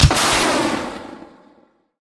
rocket-launcher-1.ogg